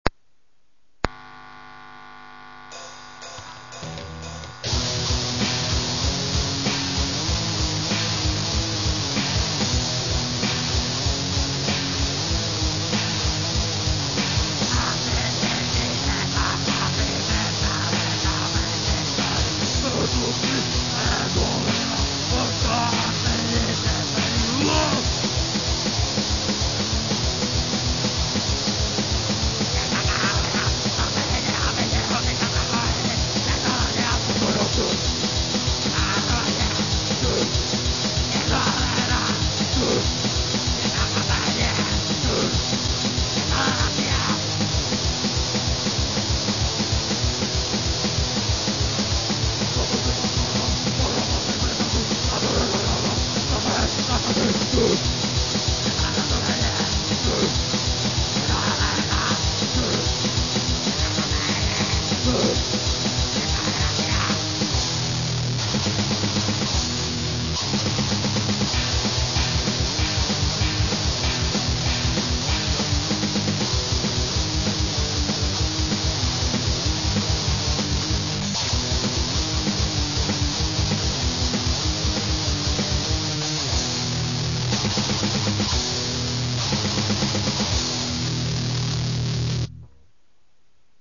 Crust